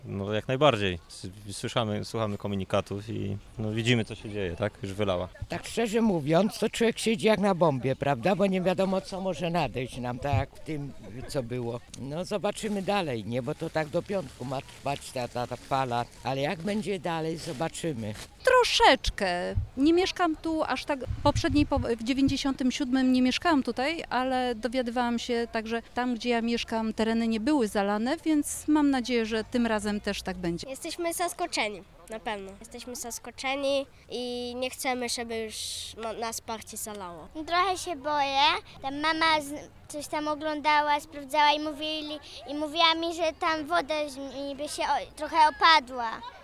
Wokół zalanej ulicy zgromadziło się sporo mieszkańców w różnym wieku. Zapytaliśmy o ich odczucia na temat tej sytuacji.